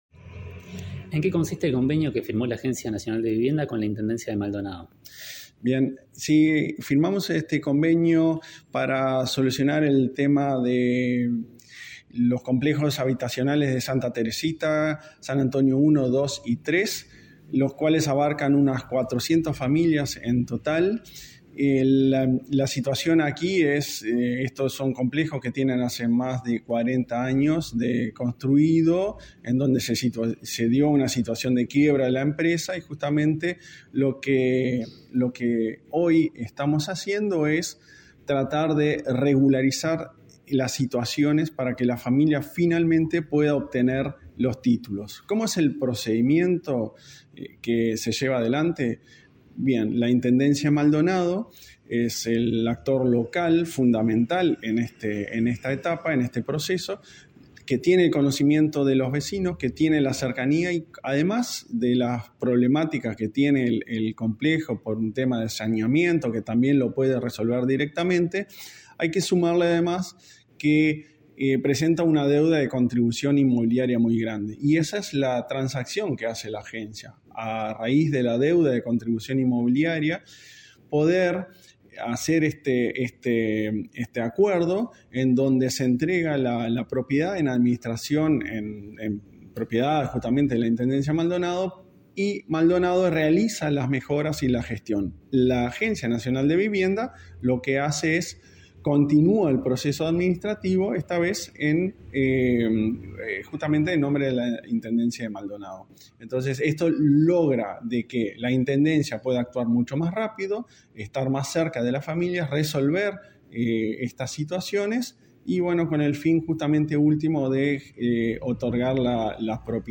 Entrevista al presidente de la ANV, Klaus Mill